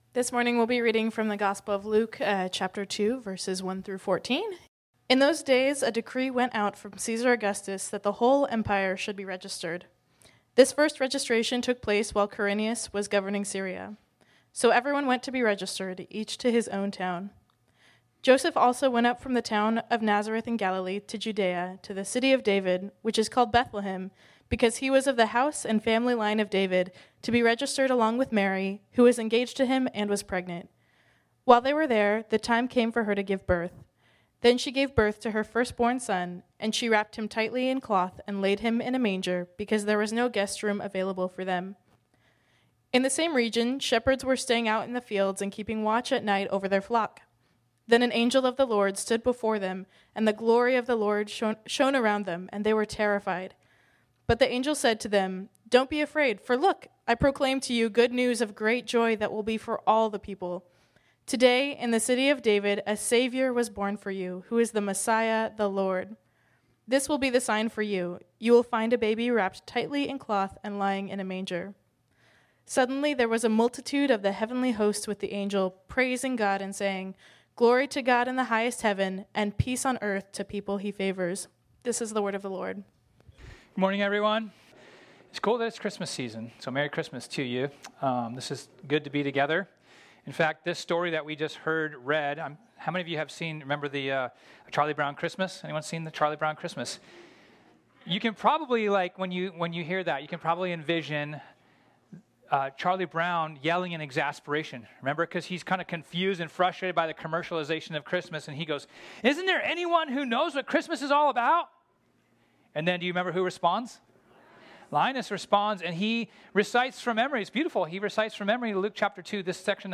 This sermon was originally preached on Sunday, December 15, 2024.